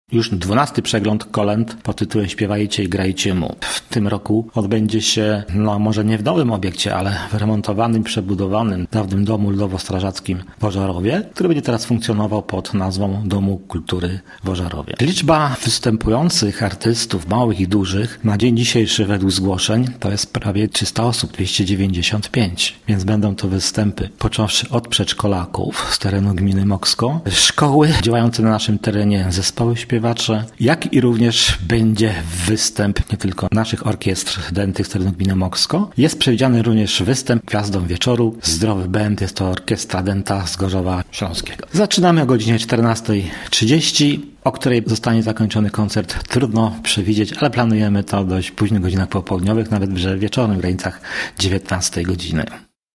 – zapraszał Zbigniew Dąbrowski, wójt gminy Mokrsko.